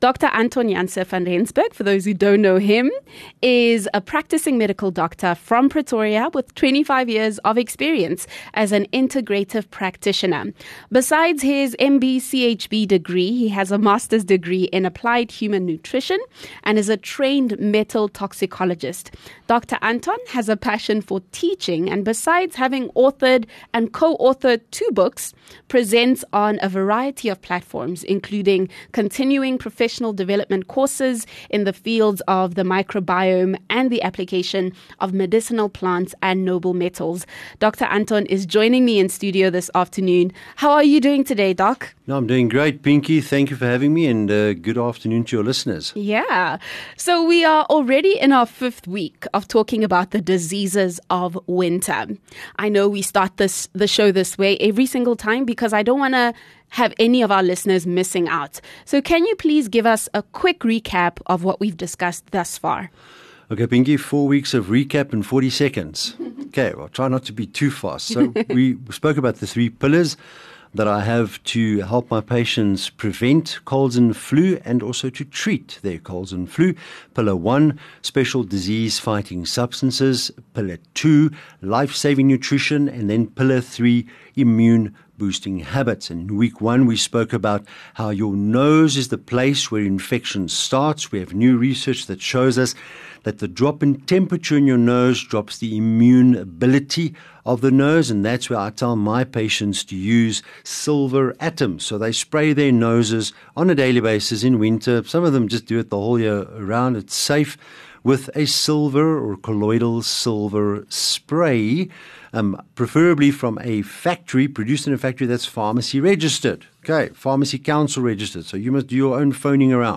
View Promo Continue Install ON-AIR CONTENT 30 Jul SilverLab Healthcare Feature